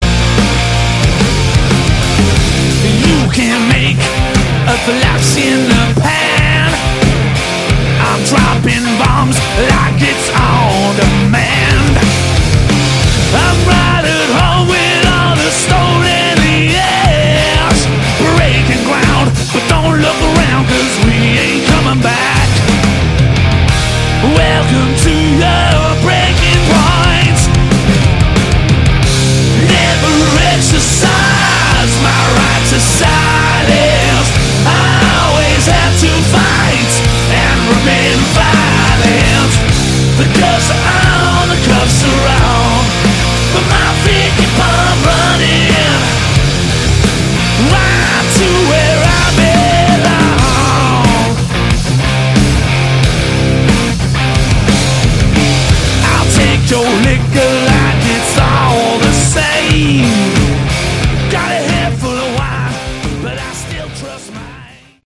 Category: Hard Rock
vocals, bass
guitars
drums